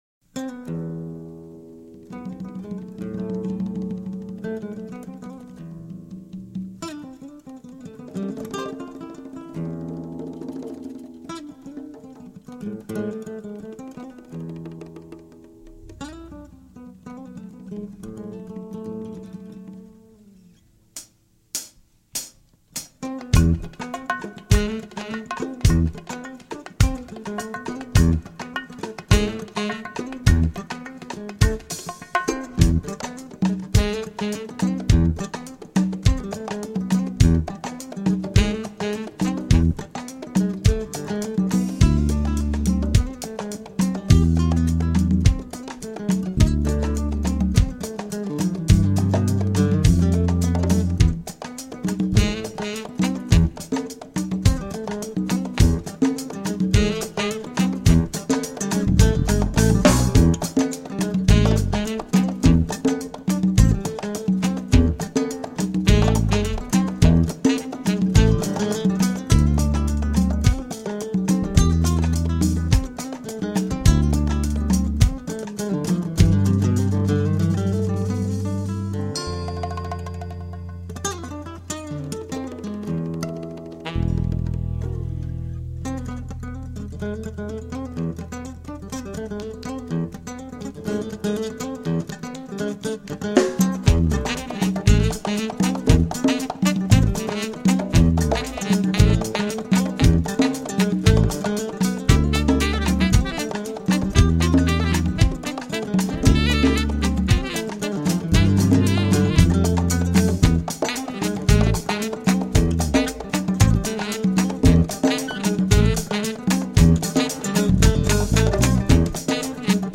bass, violin, saxophone and drums